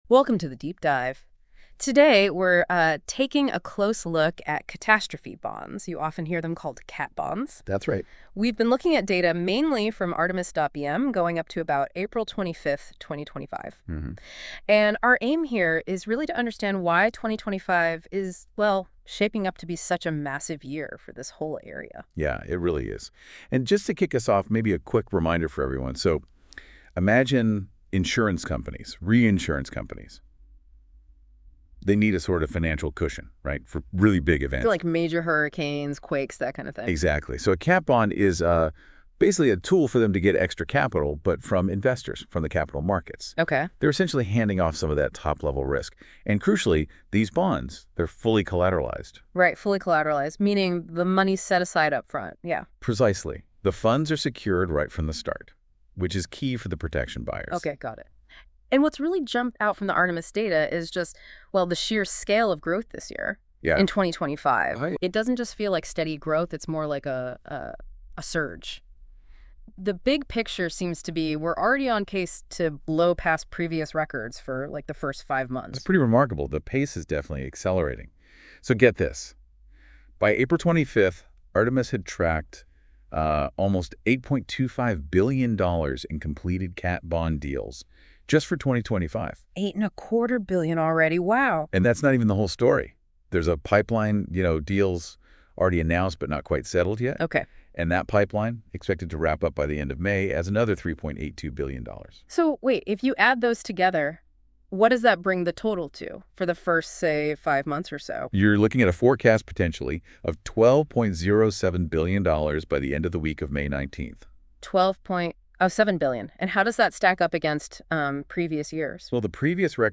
This latest podcast episode is a bit of an experiment, as we've utilised NotebookLM to transform one of our recent articles that analyses catastrophe bond issuance through April 25th 2025 into an audio podcast episode.